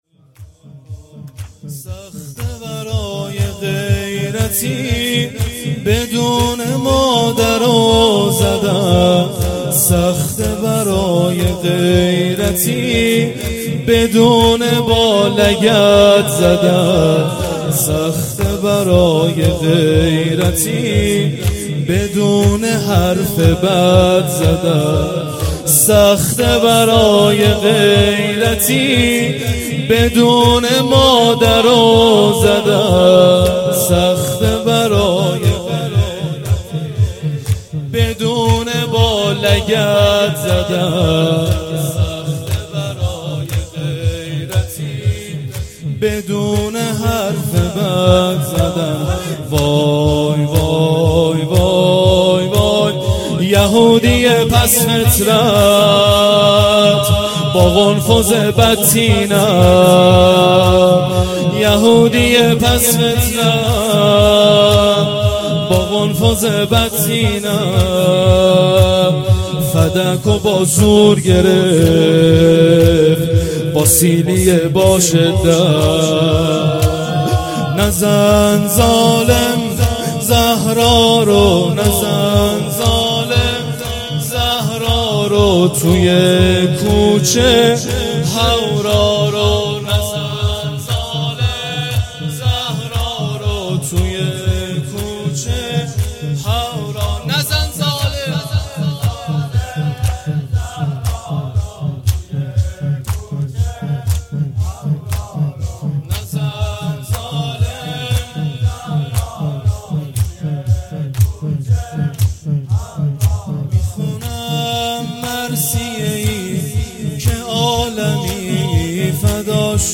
سبک جدید